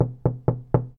敲门
描述：这是一个敲门的人